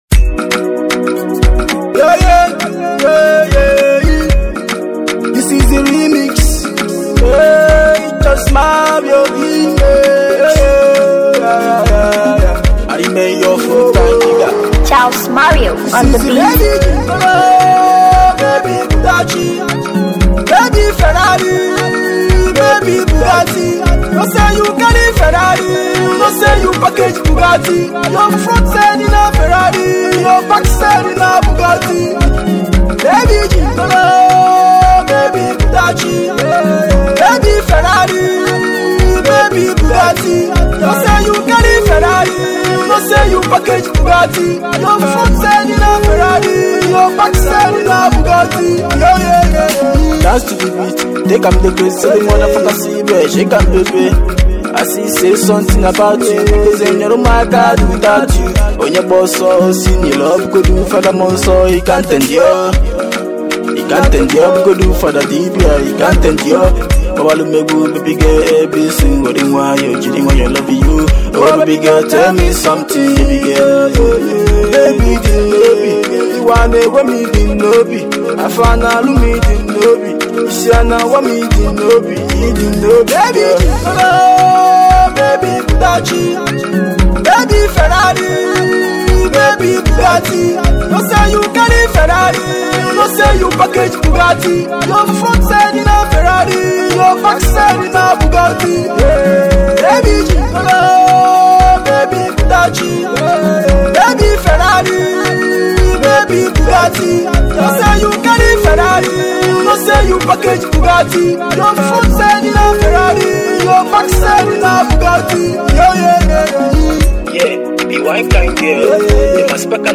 afro hip hop
cool party track